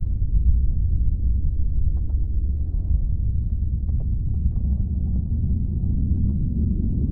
env_sounds_lava.2.ogg